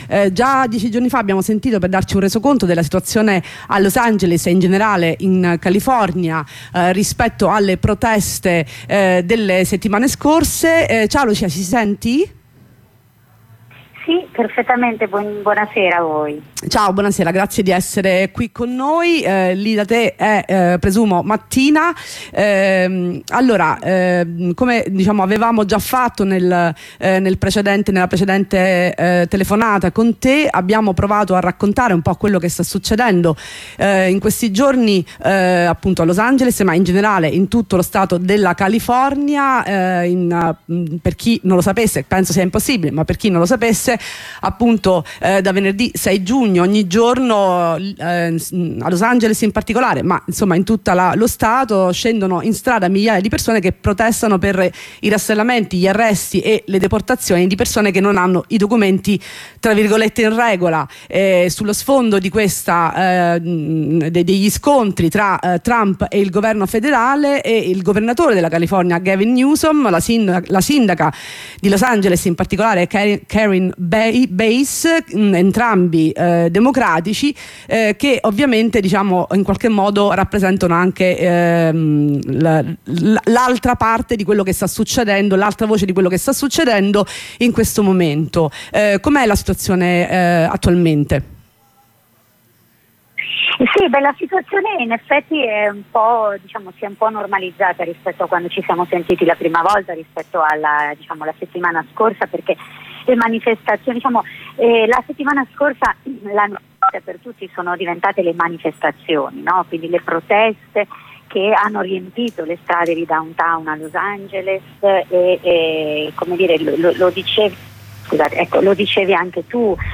corrispondenza da Los Angeles